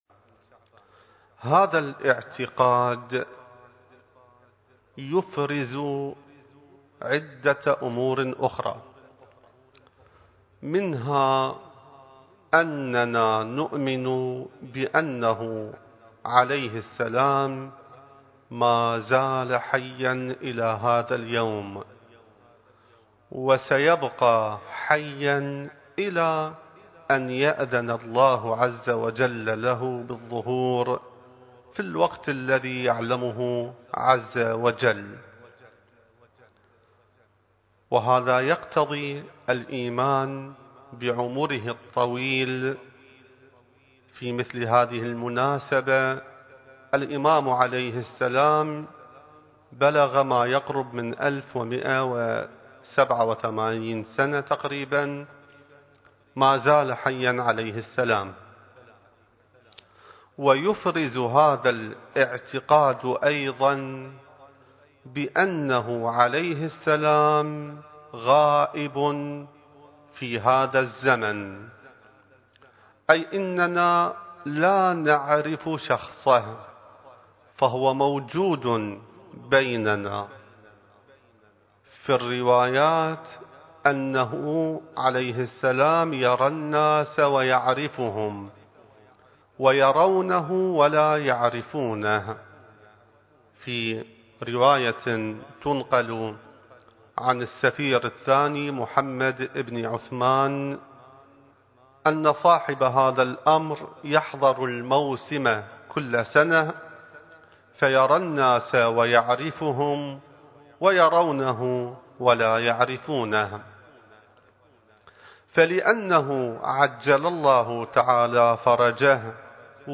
المكان: العتبة العلوية المقدسة الزمان: ذكرى ولادة الإمام المهدي (عجّل الله فرجه) التاريخ: 2021